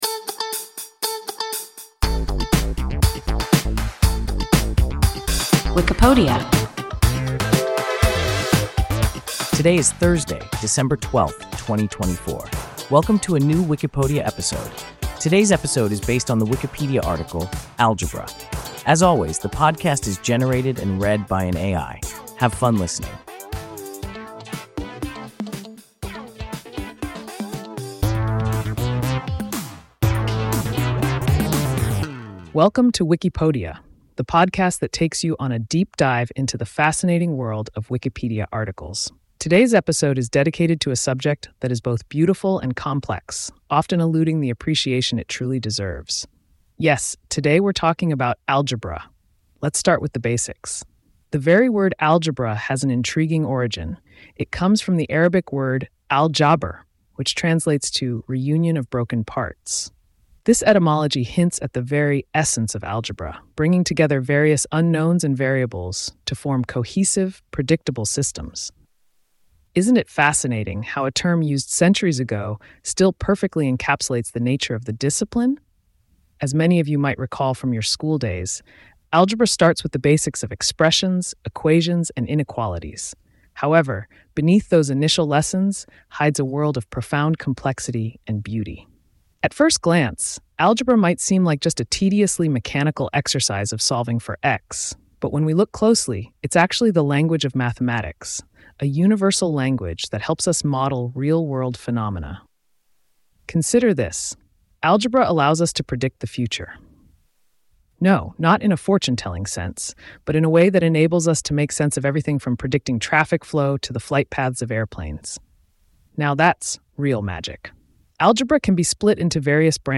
Algebra – WIKIPODIA – ein KI Podcast